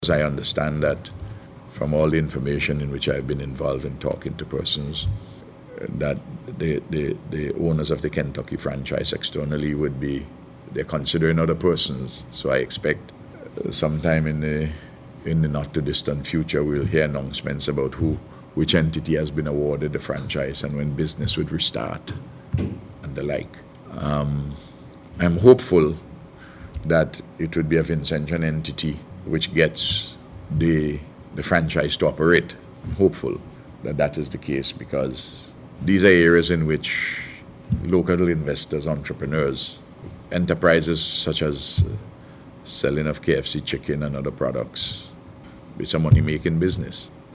Speaking at Tuesday’s News Conference, Dr. Gonsalves said he was particularly concerned about the plight of the workers.